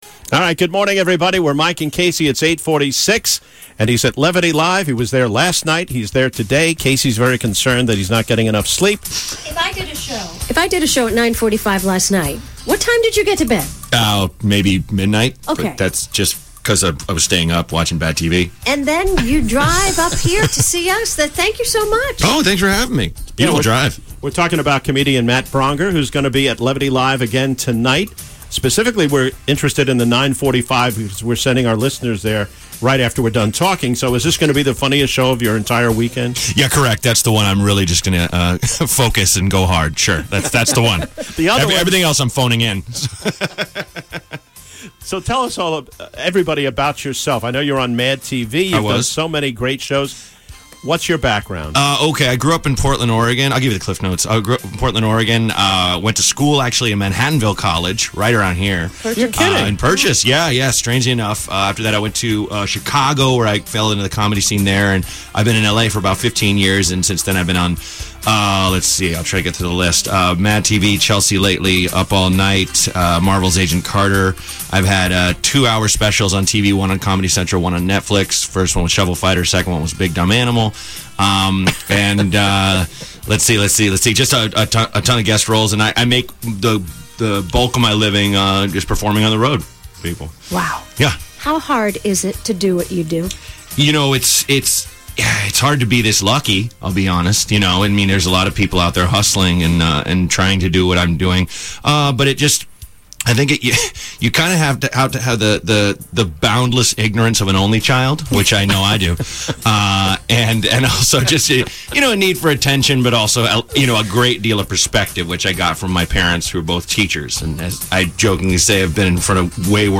Matt Braunger - Comedian-Levity Live